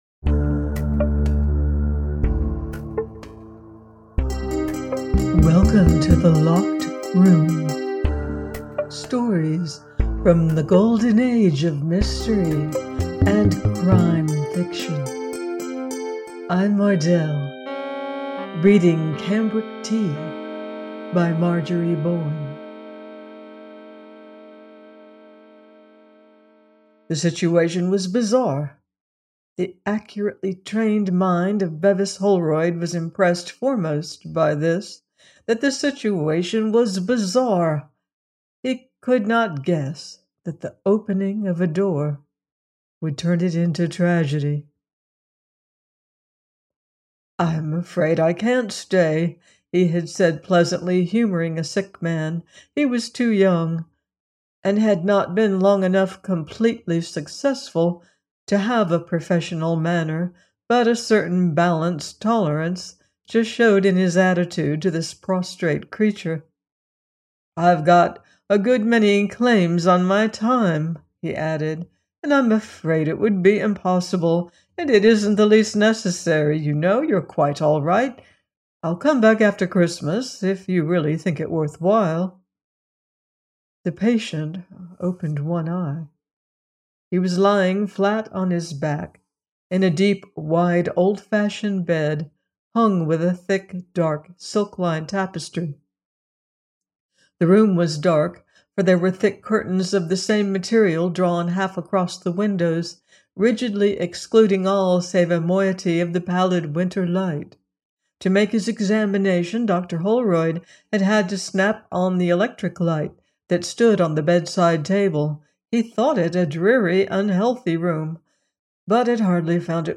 Cambric Tea – by Marjorie Bowen - audiobook